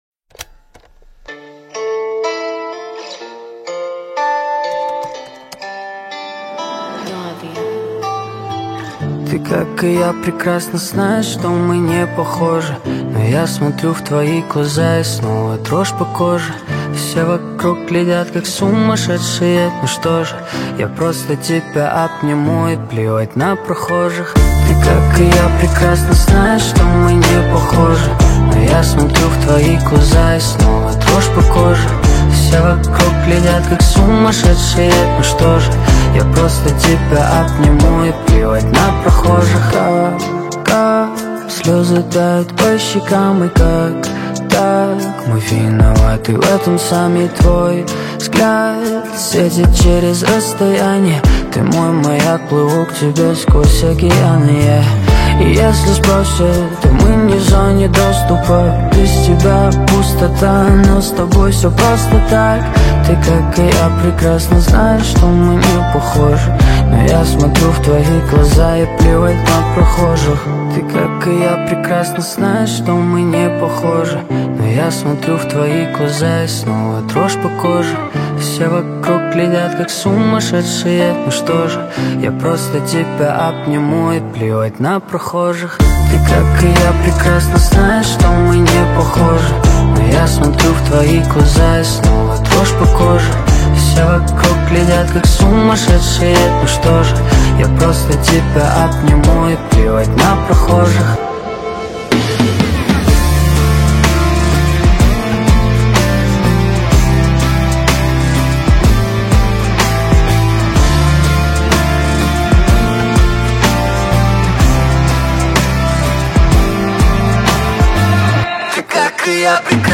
آهنگ روسی